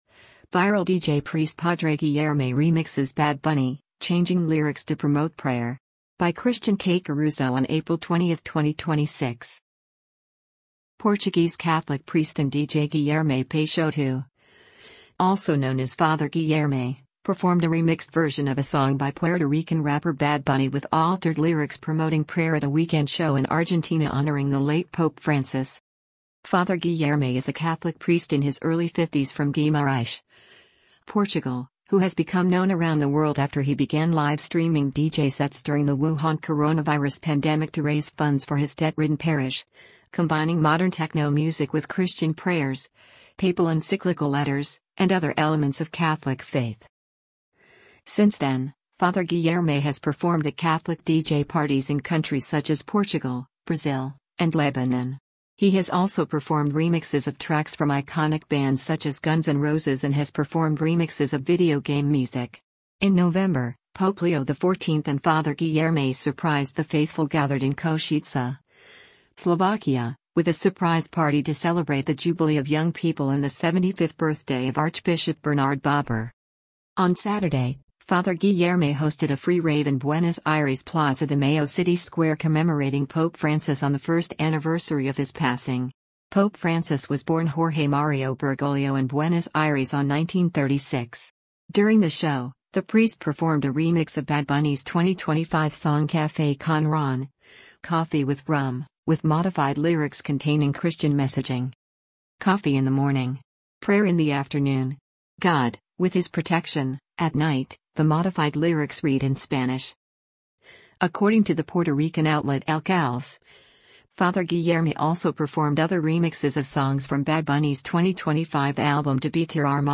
Portuguese Catholic priest and DJ Guilherme Peixoto, also known as Father Guilherme, performed a remixed version of a song by Puerto Rican rapper Bad Bunny with altered lyrics promoting prayer at a weekend show in Argentina honoring the late Pope Francis.
Father Guilherme is a Catholic priest in his early 50s from Guimarães, Portugal, who has become known around the world after he began live streaming DJ sets during the Wuhan coronavirus pandemic to raise funds for his debt-ridden parish, combining modern techno music with Christian prayers, papal encyclical letters, and other elements of Catholic faith.
On Saturday, Father Guilherme hosted a free rave in Buenos Aires’ Plaza de Mayo city square commemorating Pope Francis on the first anniversary of his passing.
The show also featured audio clips of Pope Francis’s messages throughout his papacy.